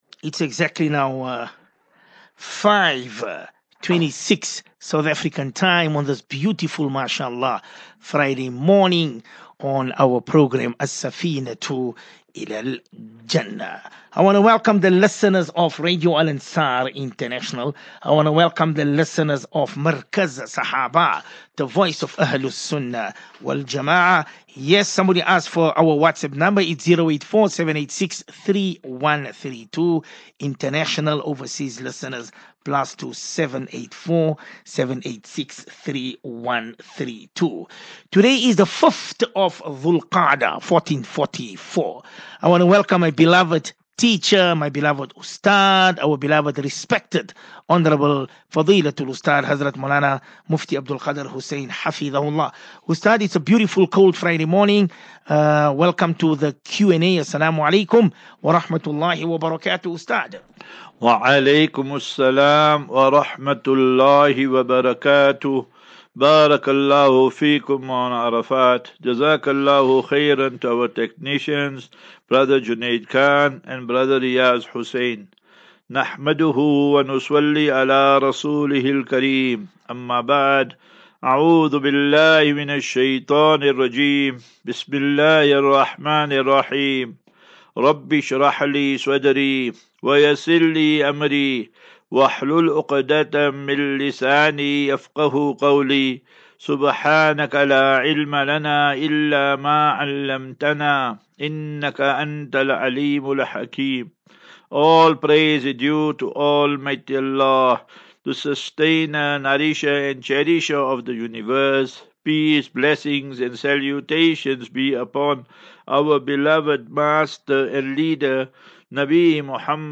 As Safinatu Ilal Jannah Naseeha and Q and A 26 May 26 May 23 Assafinatu